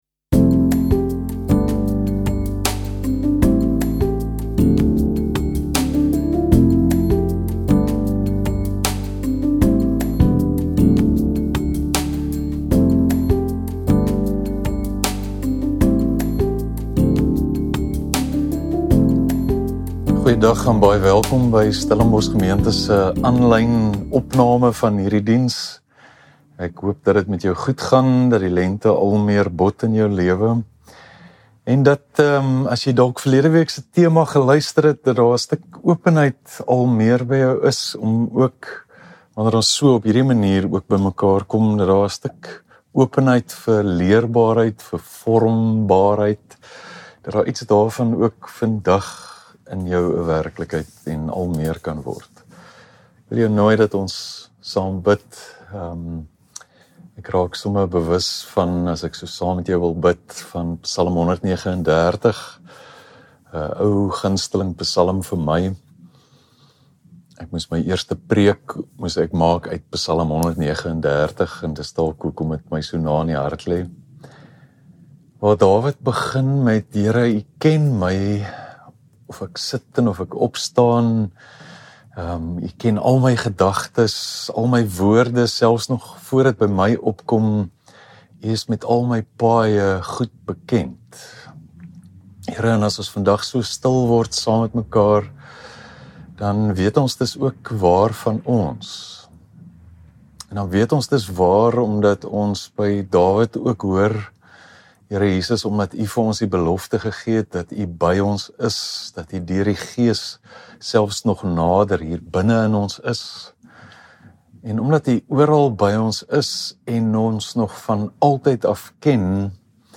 Stellenbosch Gemeente Preke